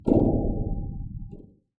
描述：使用来自freesound.Medium距离的声音重新创建光环needler
标签： 科幻 卤素 刺针
声道立体声